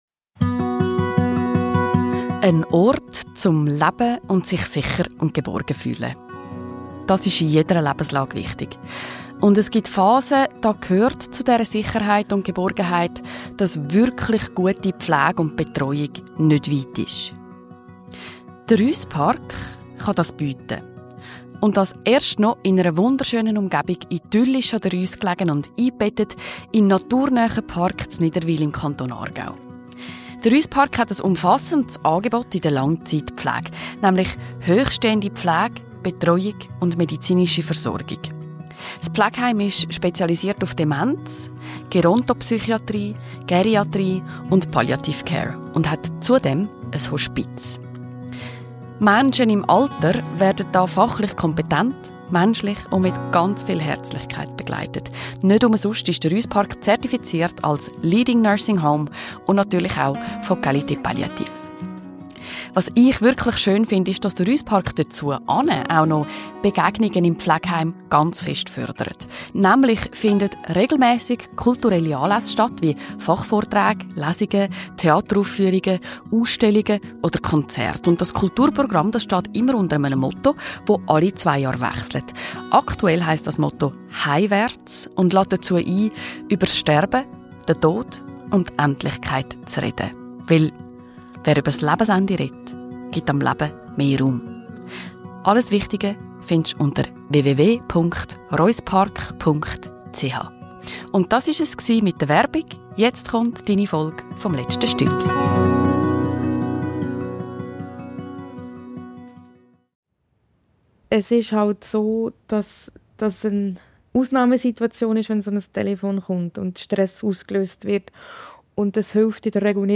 Ein Gespräch über Abschiede.